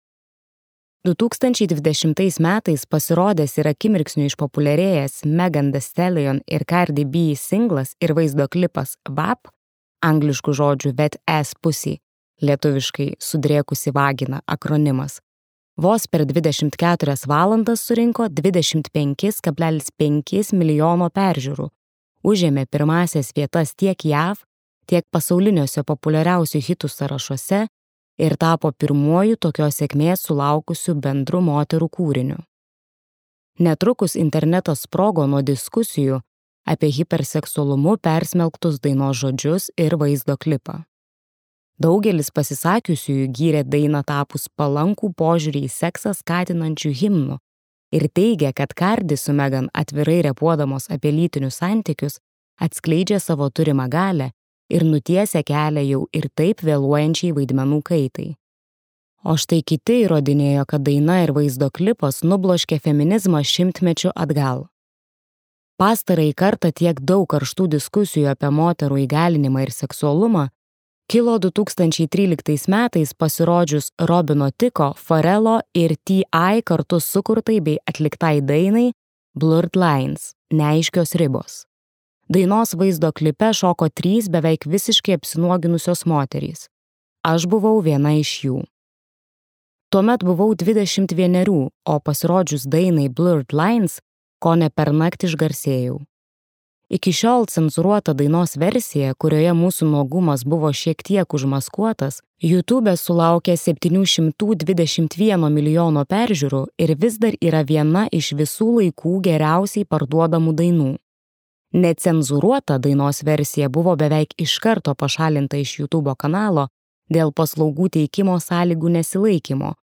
Mano kūnas | Audioknygos | baltos lankos